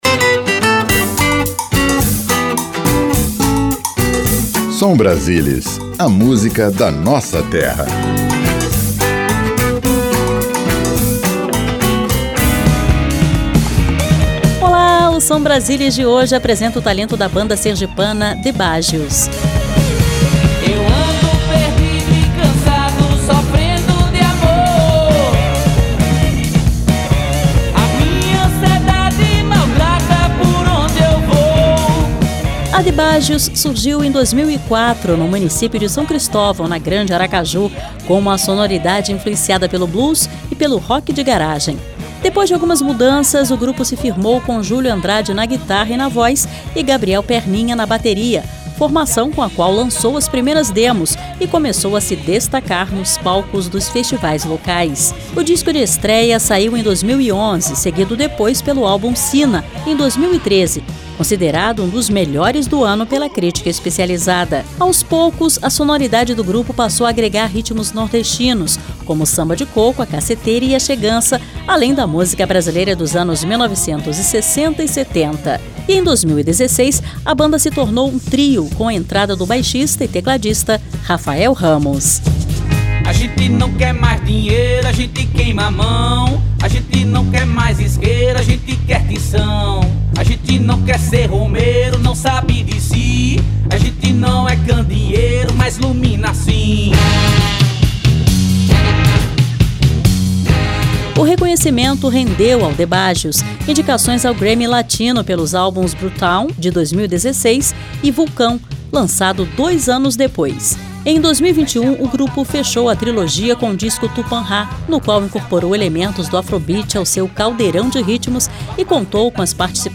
com uma mistura de blues e rock de garagem.
rock setentista
Música Brasileira